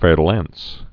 (fĕrdl-ăns, -äns)